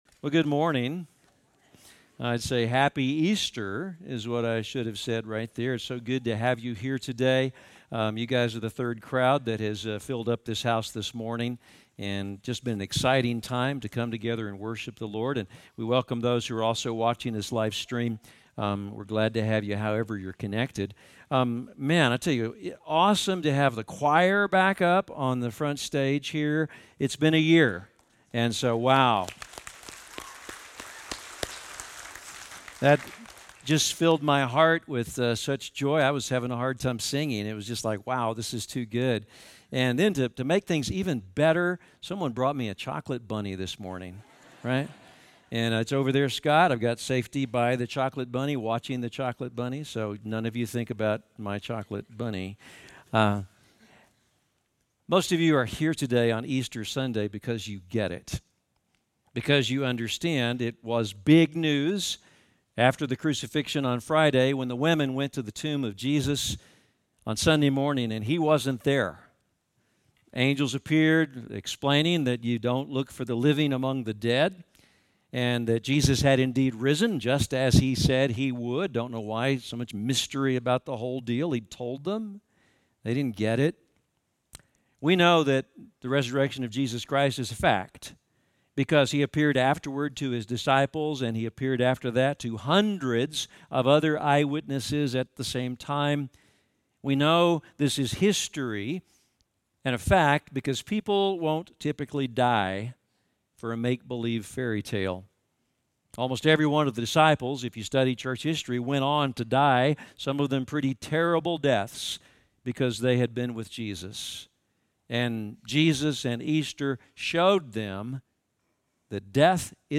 In our Easter Sunday message, we meet two Christ-followers who are traveling the road to Emmaus that first Easter Sunday evening. They are bewildered and dejected by the intense plot twist that has taken their hopeful Messiah and had him nailed to a cross. On the road they meet a “stranger” who comes alongside them and gently challenges the story they expected.